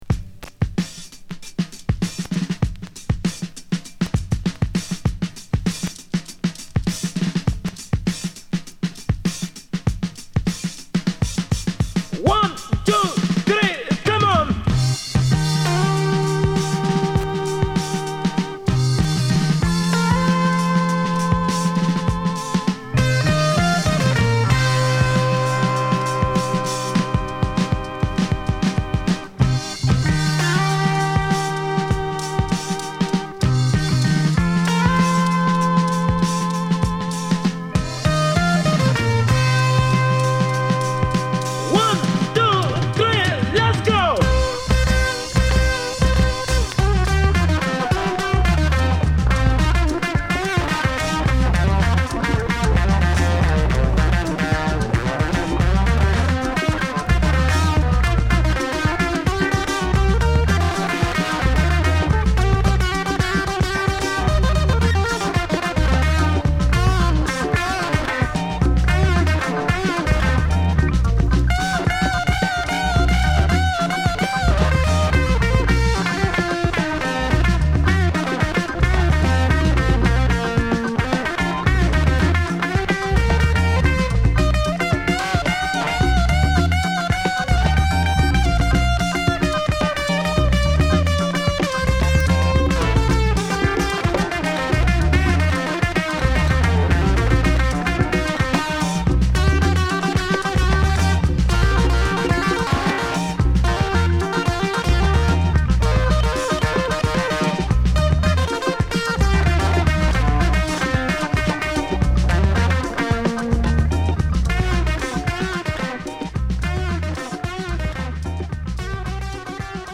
盤は所々ノイズの出る箇所が有りますが、ツヤも残っており全体はナイジェリア盤にしてはかなり綺麗な状態かと思います。